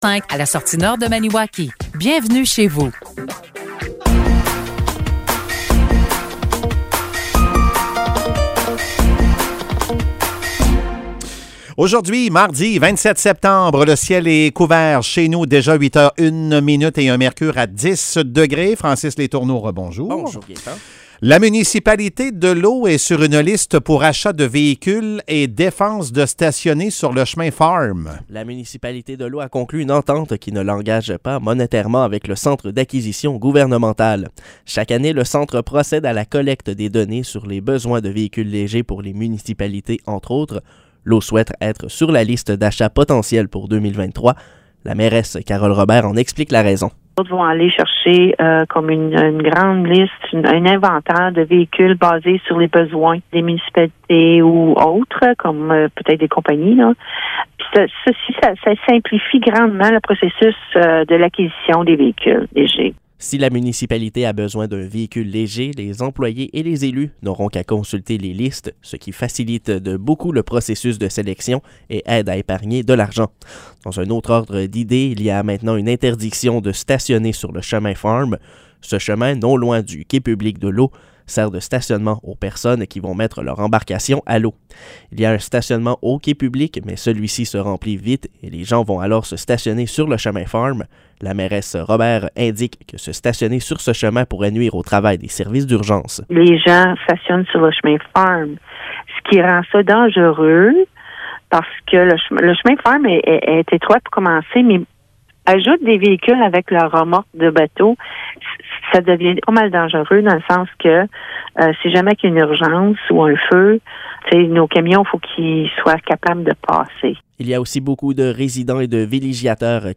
Nouvelles locales - 27 septembre 2022 - 8 h